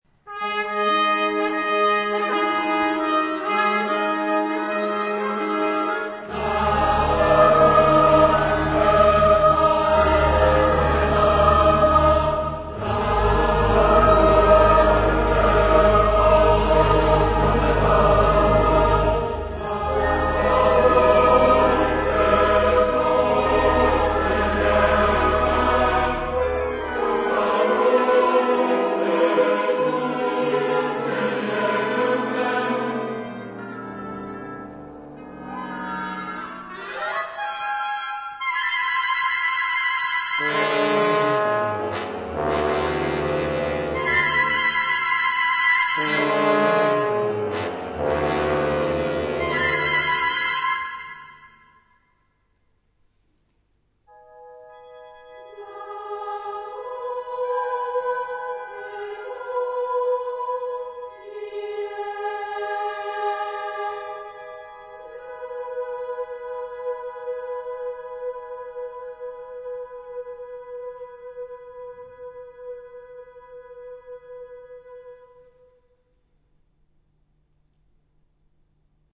Genre-Style-Forme : Sacré ; Oratorio
Type de choeur : SATB  (4 voix mixtes )
Instrumentation : Orchestre  (20 partie(s) instrumentale(s))